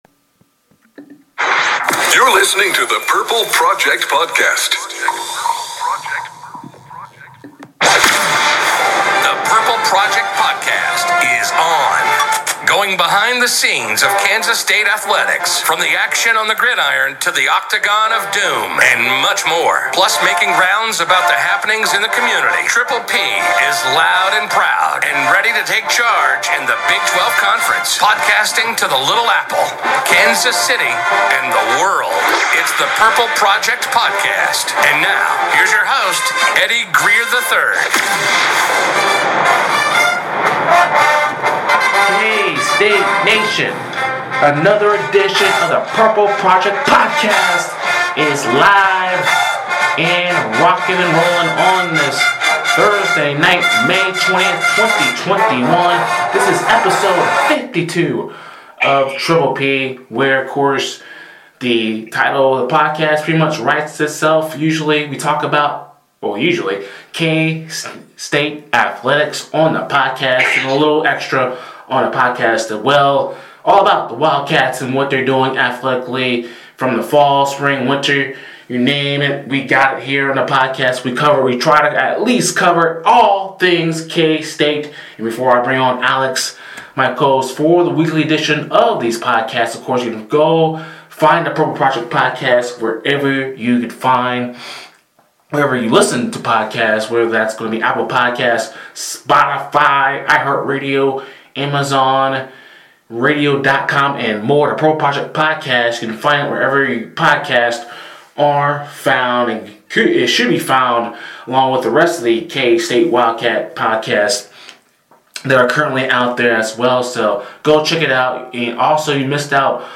The guys chat after Game 1 of the final series of the regular season vs. TCU. Also, a critical sports season is coming up in the Little Apple plus Jordy Nelson & more.